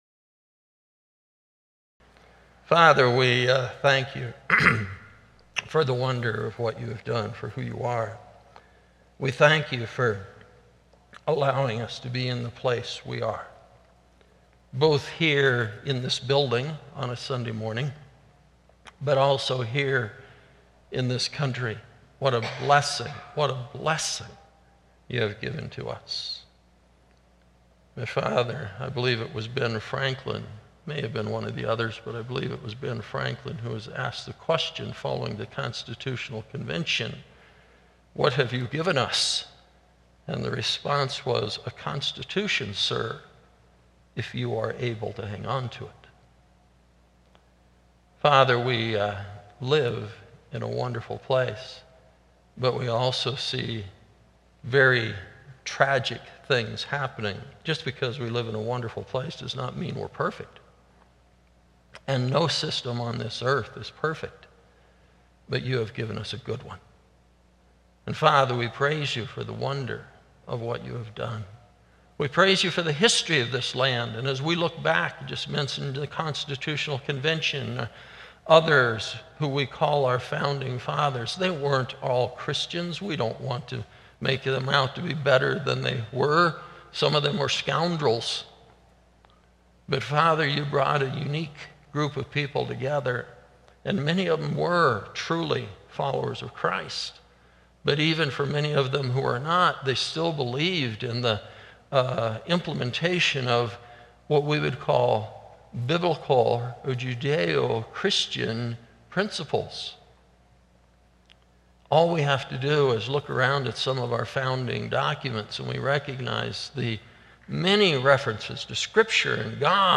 Weekly Sermons - Evangelical Free Church of Windsor, CO